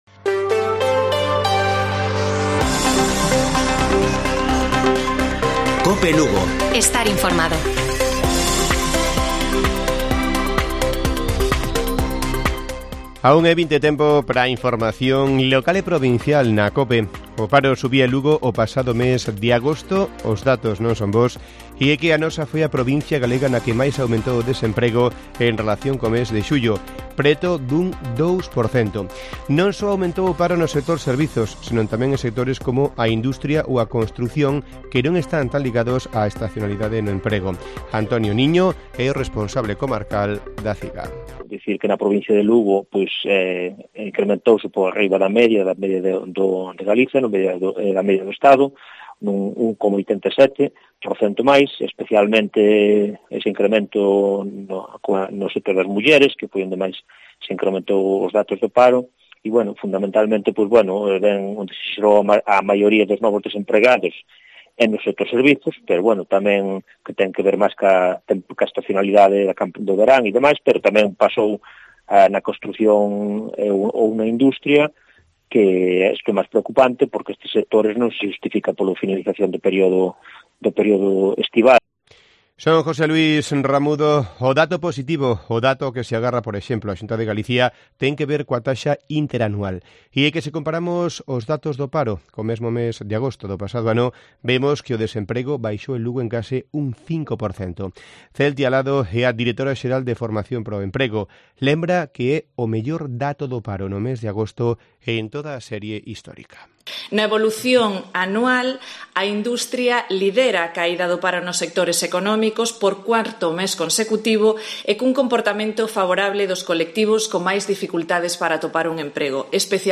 Informativo Mediodía de Cope Lugo. 02 de septiembre. 13:20 horas